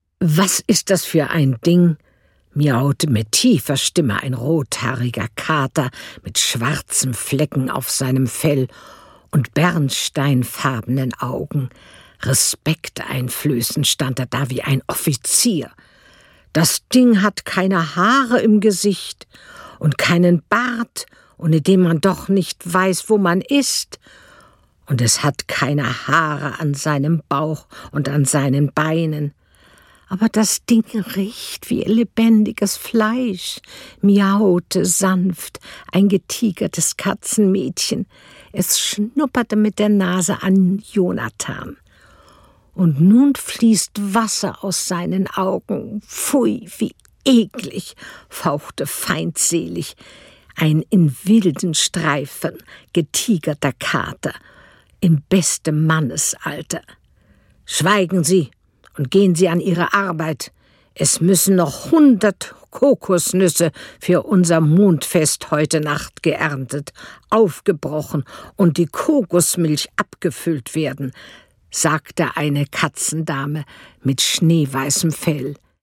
Female
Kindergeschichte